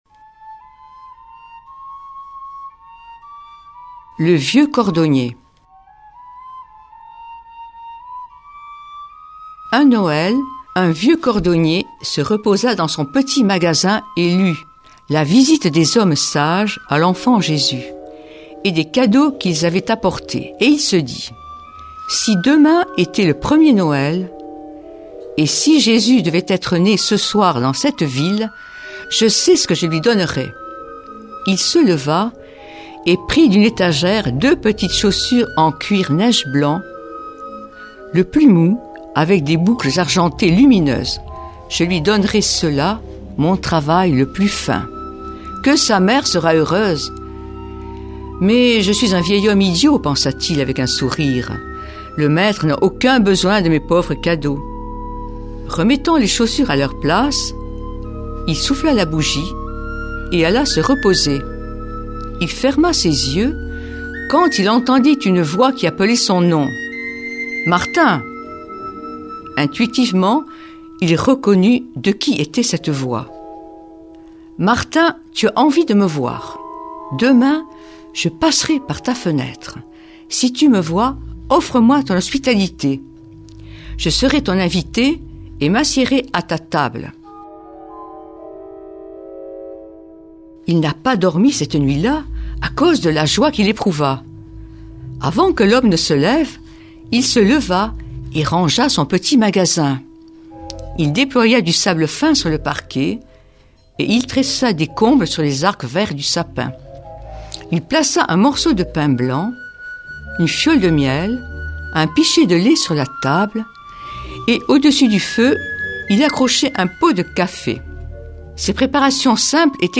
mardi 1er janvier 2019 Contes de Noël Durée 8 min
Le vieux cordonnier suivi d’un chant basque